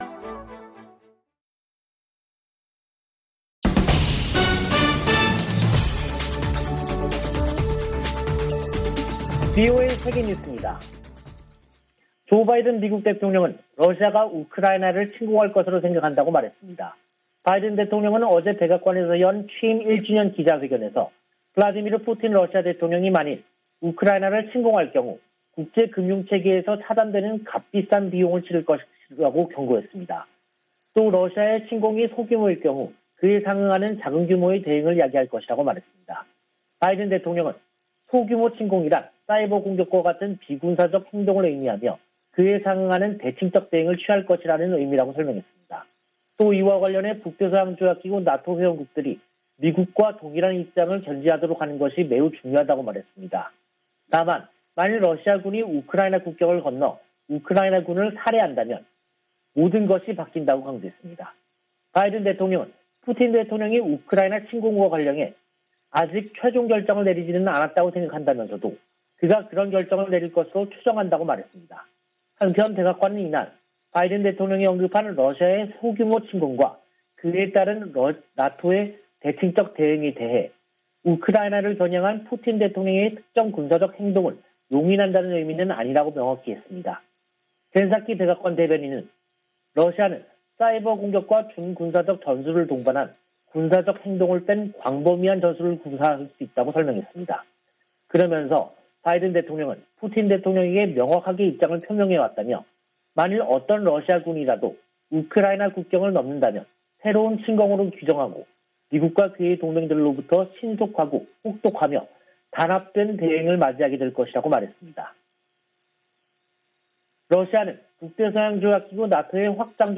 VOA 한국어 간판 뉴스 프로그램 '뉴스 투데이', 2022년 1월 20일 2부 방송입니다. 북한이 핵과 ICBM 시험 등의 유예를 철회할 것을 시사하며 대미 압박을 강화하고 있습니다. 미 공화당 중진 상원의원이 북한의 미사일 시험에 대해 핵전쟁 승리를 위한 전술무기 개발 목적이라고 경고했습니다. 유럽연합(EU)이 유엔 안보리 긴급 회의 개최와 관련해 북한의 대량살상무기(WMD) 확산 방지를 위해 노력할 것이라는 점을 재확인했습니다.